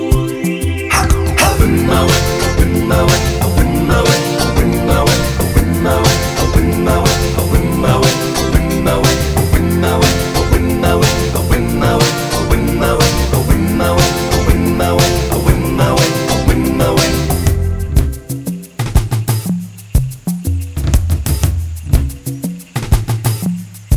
Professional Pop (1980s) Backing Tracks.